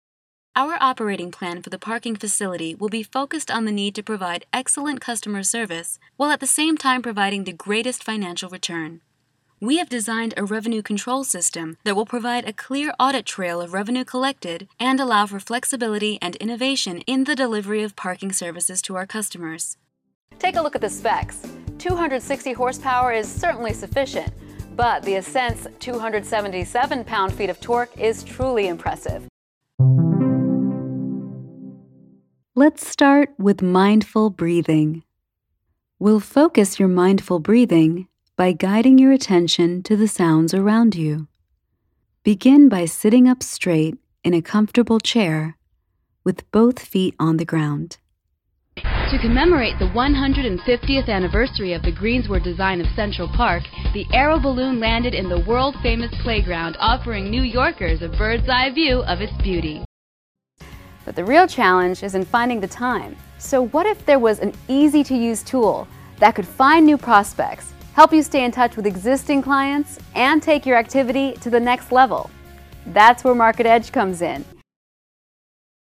Corporate Voiceover Reel